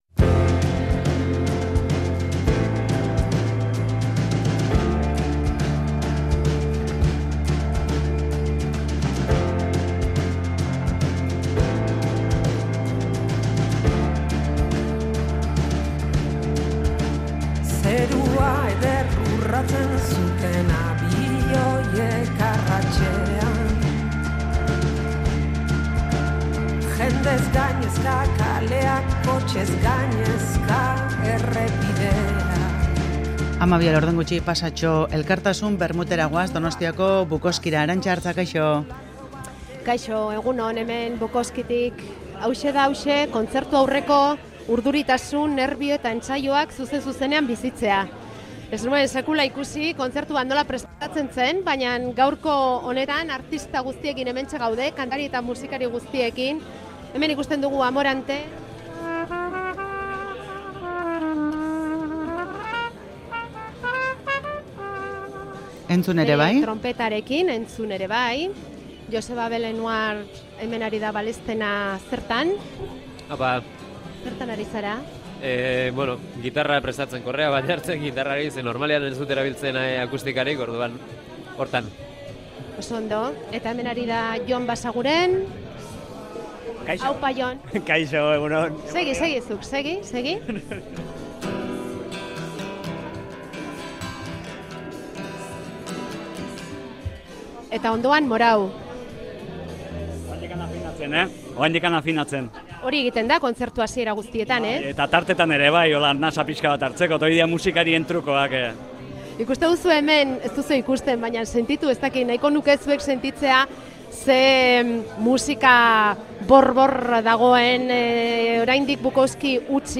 Audioa: Amarauna Bukowskiko elkartasun kontzertuaren prestaketara jetsi da, EHZ jaialdiari laguntzeko antolatu duten jaialdira.
Bertan, kontzertua eskaini behar duten artistekin hitz egin dute.